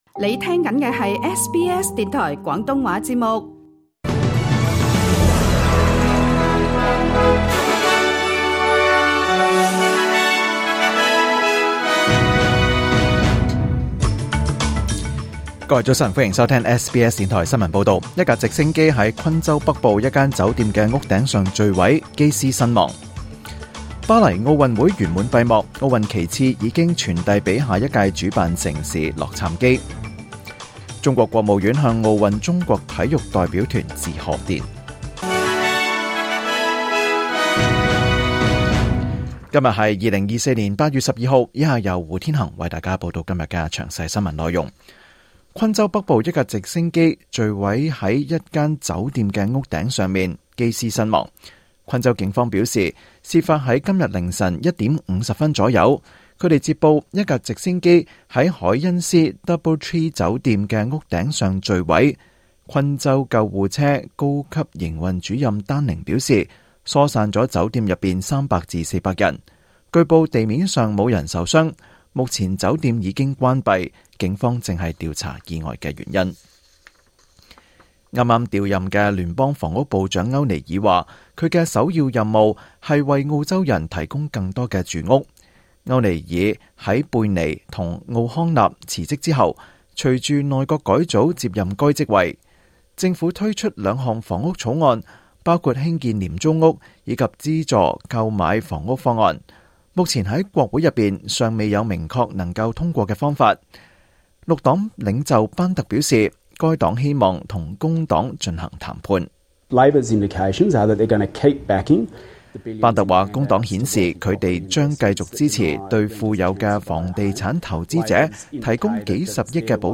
2024年8月12日SBS廣東話節目詳盡早晨新聞報道。